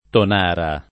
[ ton # ra ]